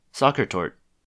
Sachertorte (UK: /ˈzæxərtɔːrtə/ ZAKH-ər-tor-tə, US: /ˈsɑːkərtɔːrt/
En-us-Sachertorte.ogg.mp3